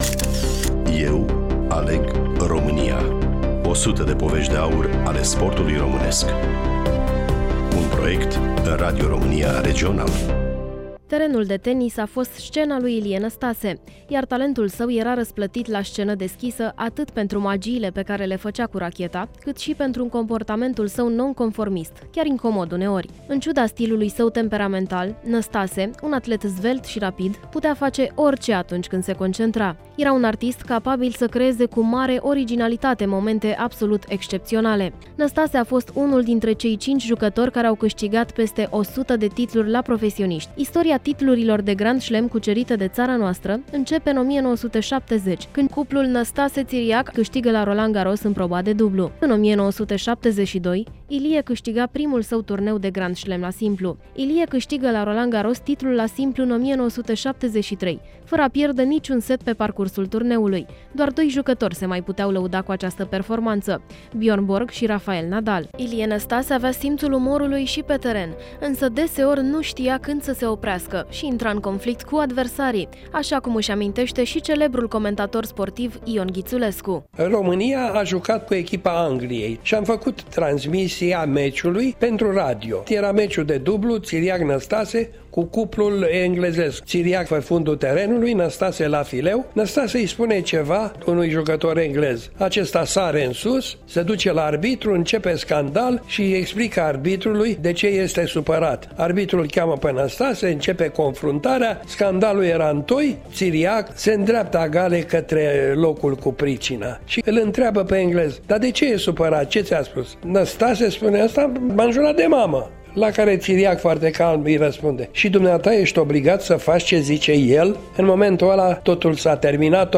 Prezentator
Voice over